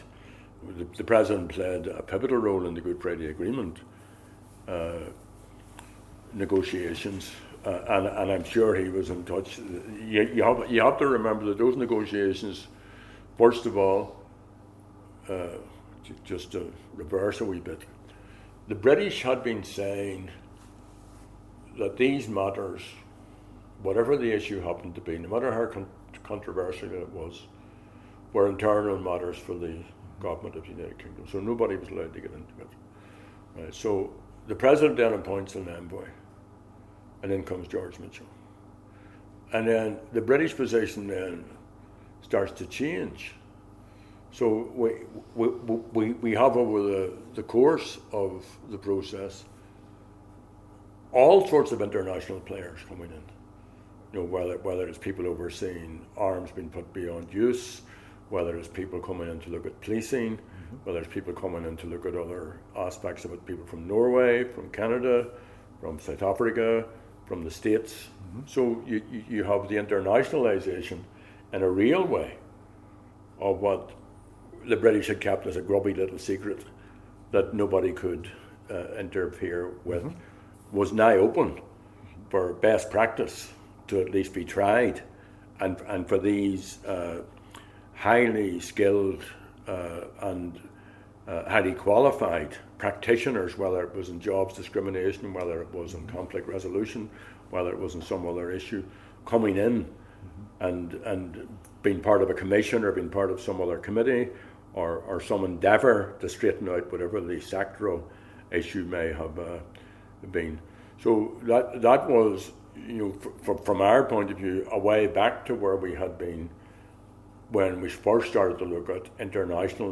He describes how President Bill Clinton’s efforts enabled progress on ending the decades-long Northern Ireland conflict. Date: November 7, 2019 Participants Gerry Adams Associated Resources Gerry Adams Oral History The Bill Clinton Presidential History Project Audio File Transcript